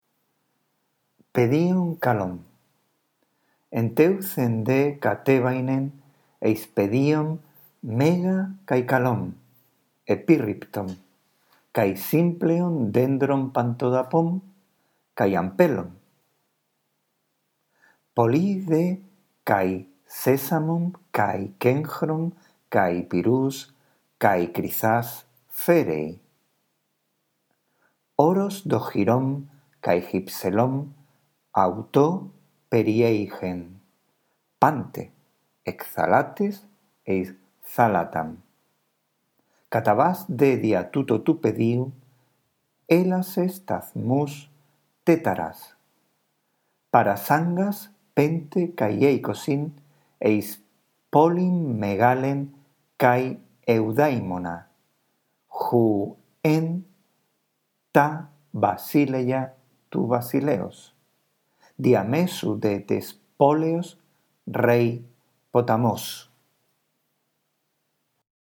Después de escuchar con atención nuestra lectura del texto griego, lee tú despacio procurando pronunciar correctamente cada palabra y entonando cada una de las oraciones.